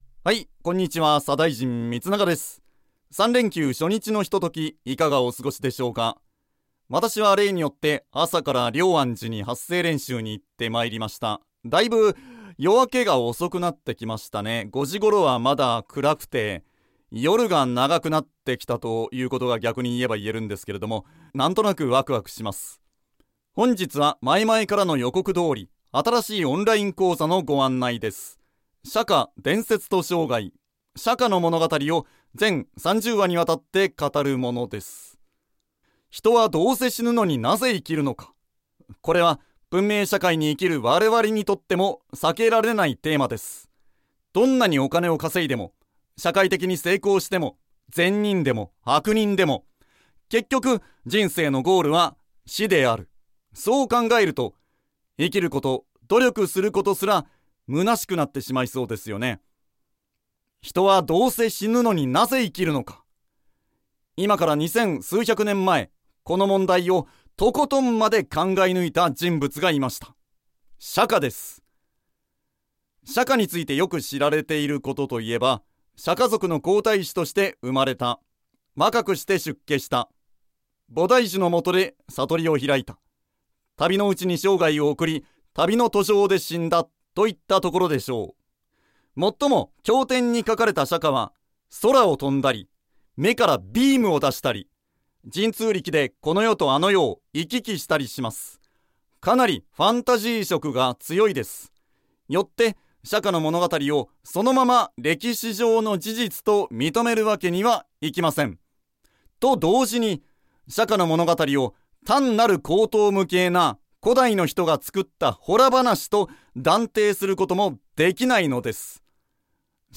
楽しく躍動感ある語りで好評をはくす。